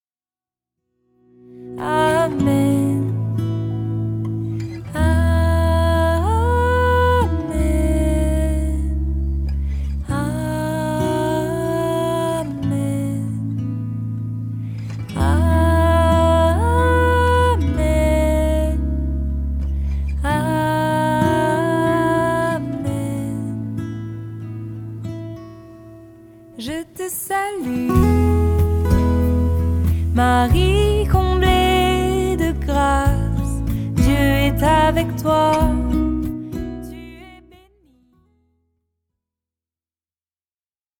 un Ave Maria moderne